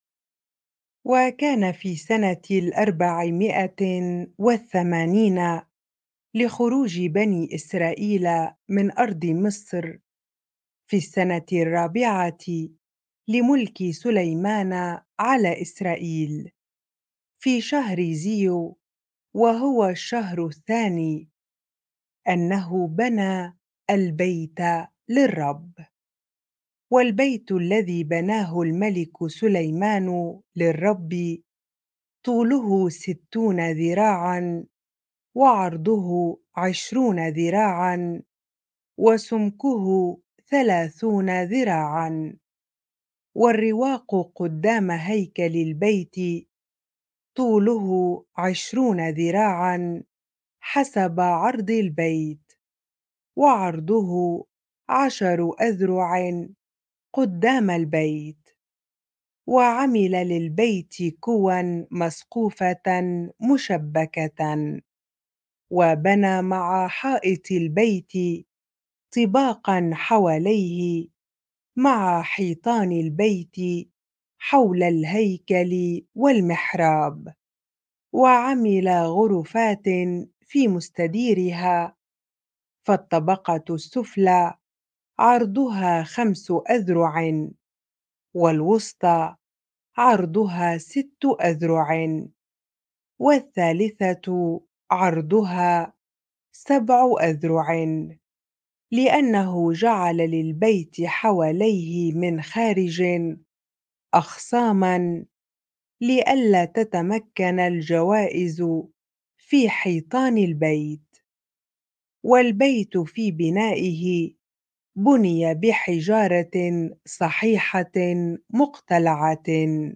bible-reading-1 Kings 6 ar